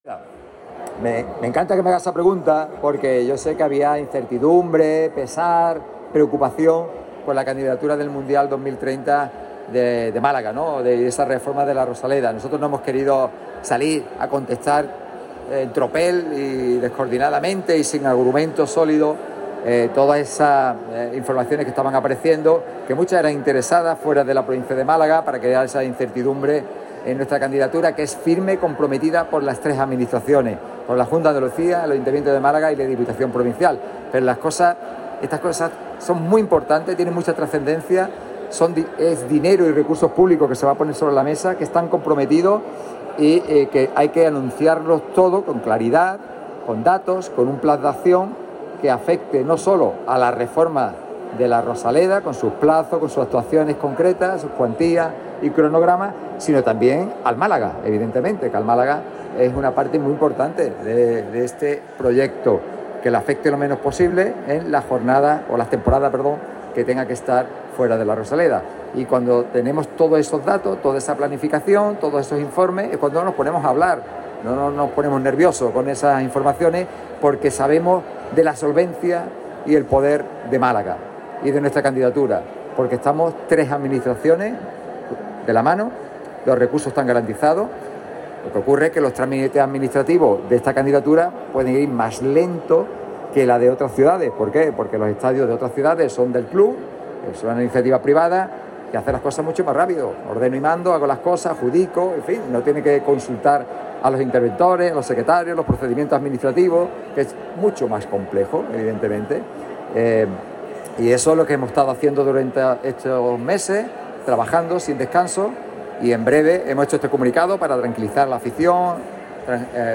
El presidente de la Diputación de Málaga hablaba del asunto ante los medios en unas declaraciones que ha podido recoger Radio MARCA Málaga.